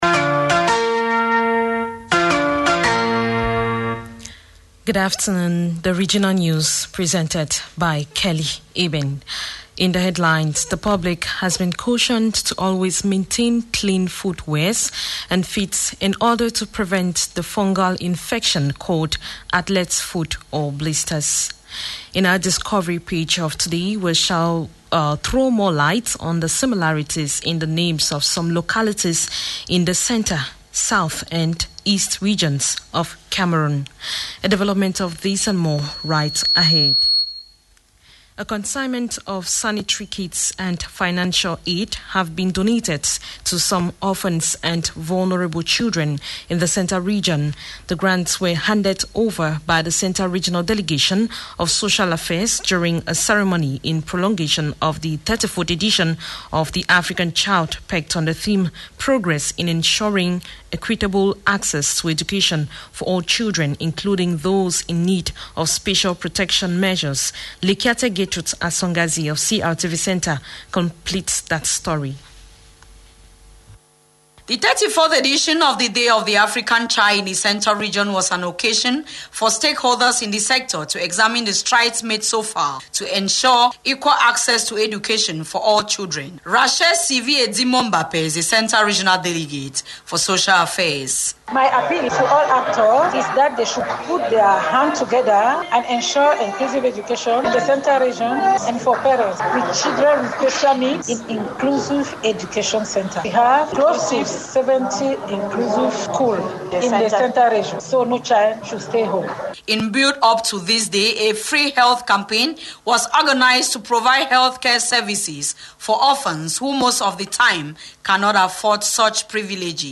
The 2pm- regional News of July 11, 2024 - CRTV - Votre portail sur le Cameroun